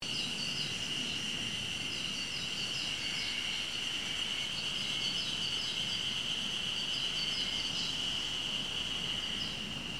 haruzemi2_rs.mp3